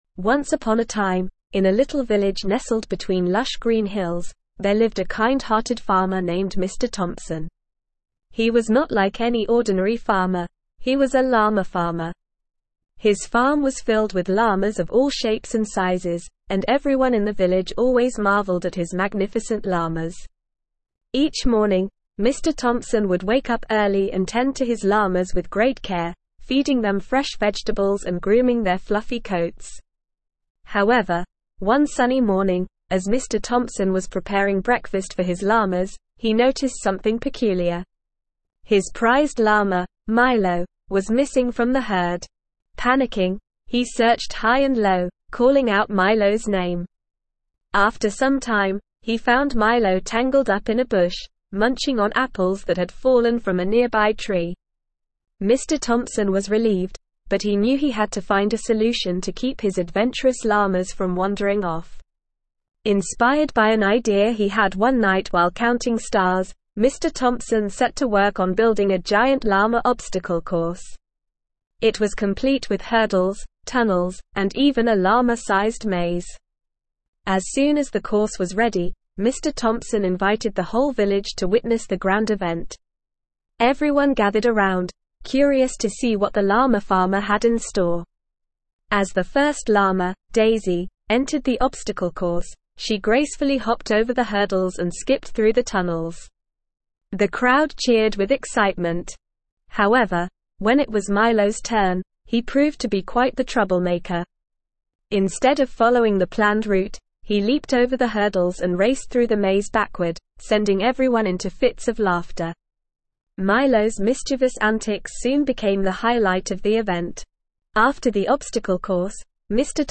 ESL-Short-Stories-for-Kids-Advanced-NORMAL-Reading-The-Llama-Farmer.mp3